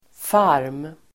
Ladda ner uttalet
farm substantiv, farm Uttal: [far:m] Böjningar: farmen, farmar Synonymer: gård, ranch Definition: lantgård (i USA); ställe där man föder upp djur Avledningar: farmare (farmer) Sammansättningar: minkfarm (mink farm)